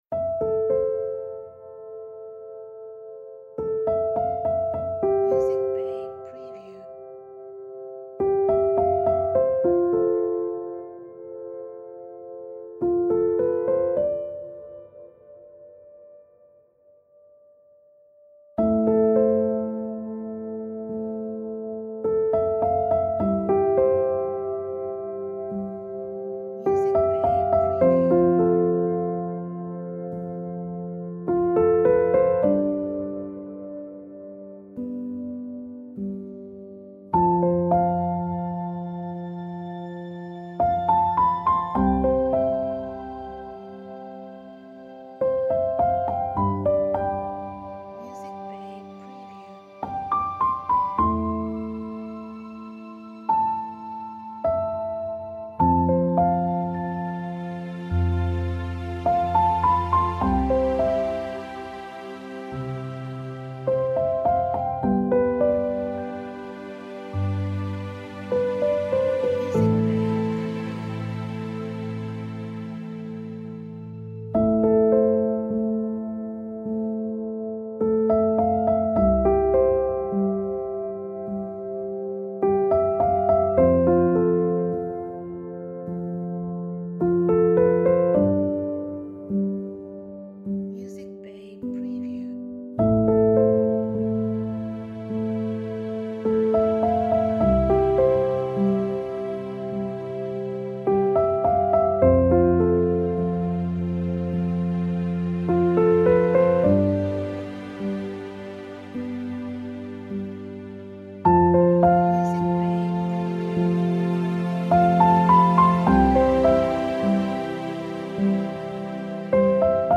Emotional background music. Romantic royalty free music.
Tempo (BPM): 80